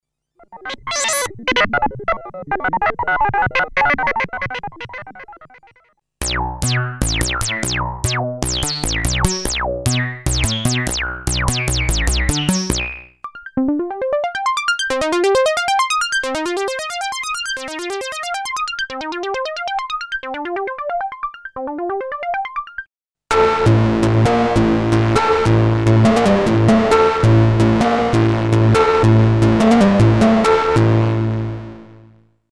Pulse_misc: Miscellaneous sounds making use of the vast modulation possibilities and the arpeggiator.